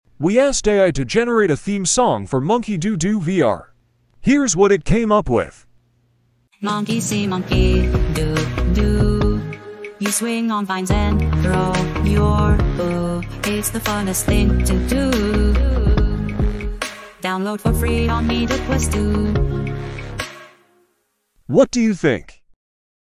Song by A.I.